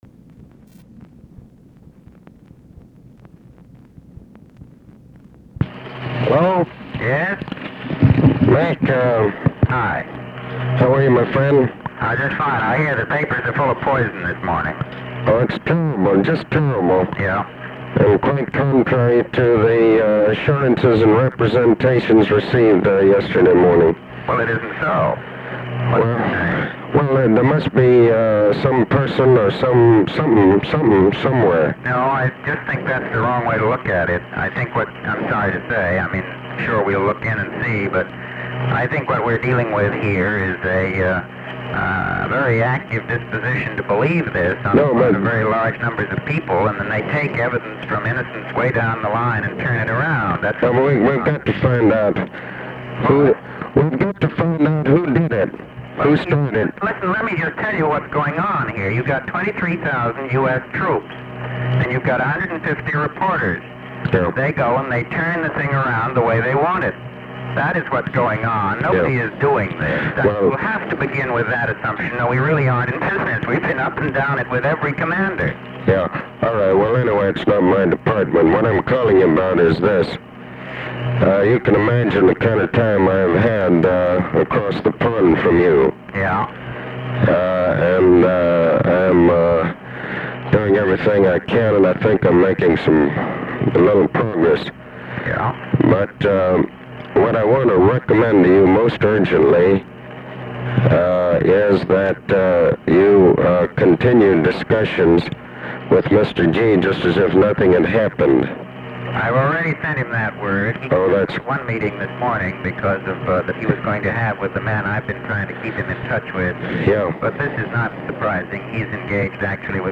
Conversation with MCGEORGE BUNDY, BROMLEY SMITH and ABE FORTAS
Secret White House Tapes